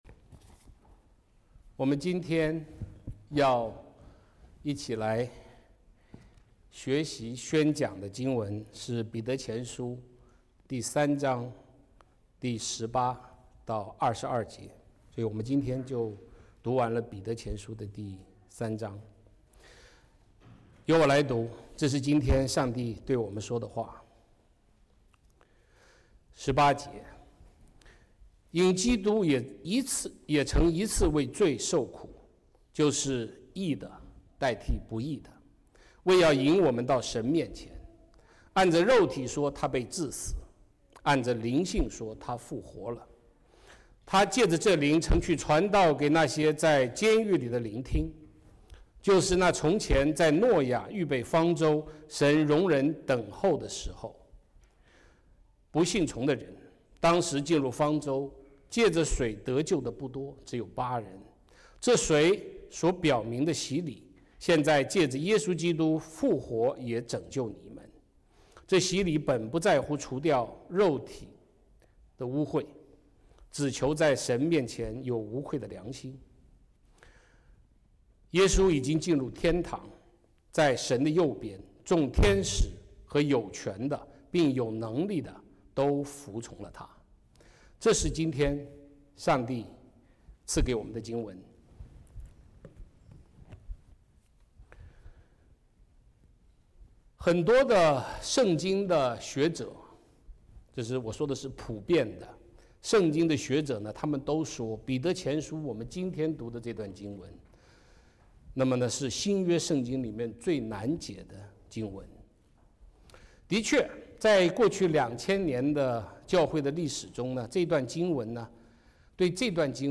彼得书信 Service Type: Sermons 2026年1月4日 | 主日1 圣道宣讲： 彼得前书3:18-22 | 基督的荣耀 « 多特信经｜55五项教义